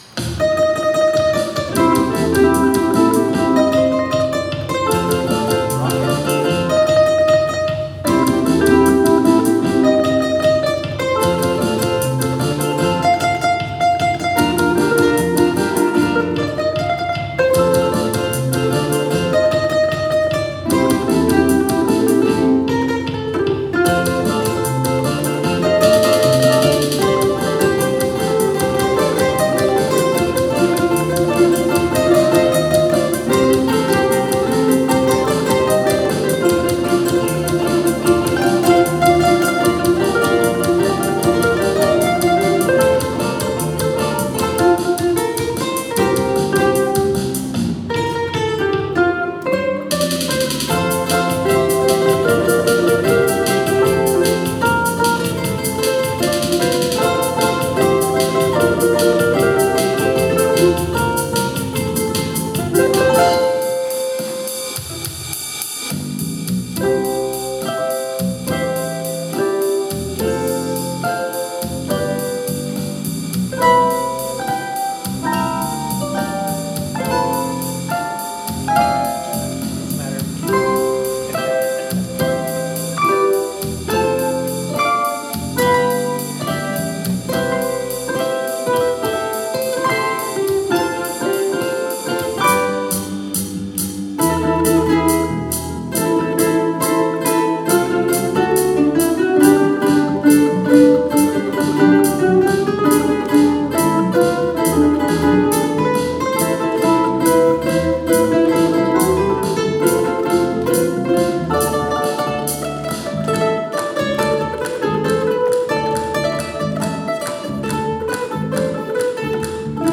The California surf rock
soaring harmonies